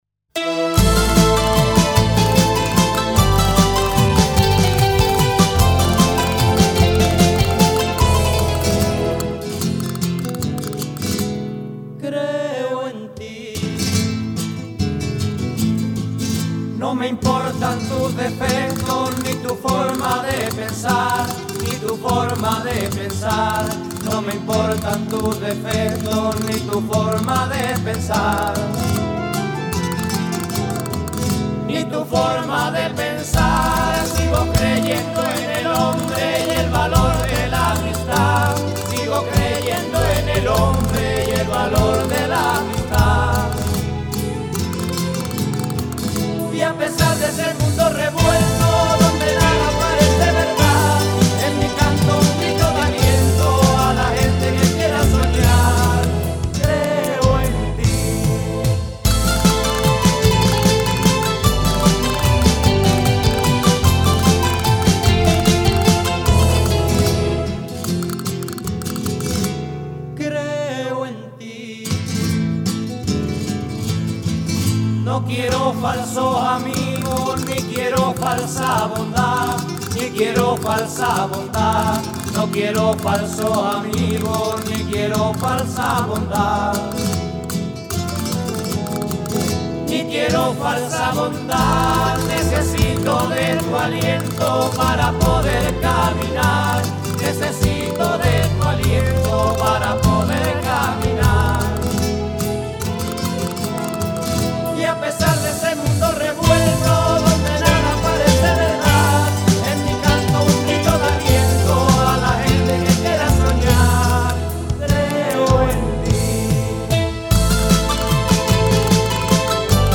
Sevillana